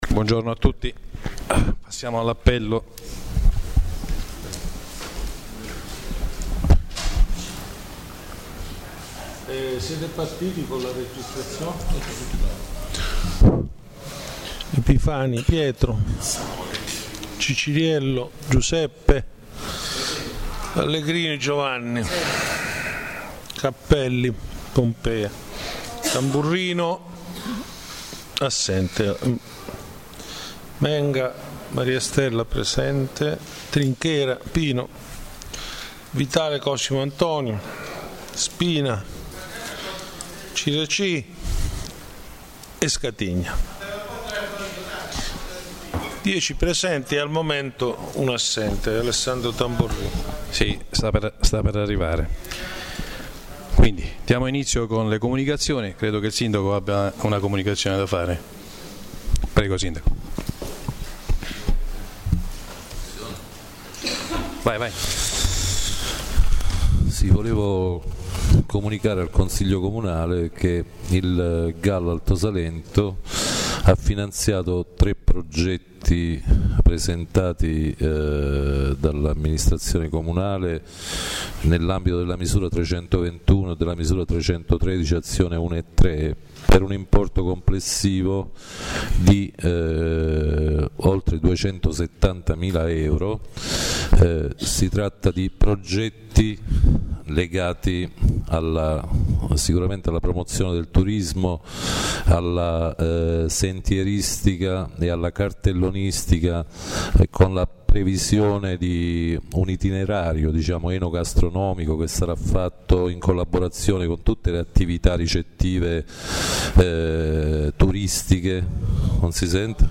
La registrazione audio del Consiglio Comunale di San Michele Salentino del 30/11/2013: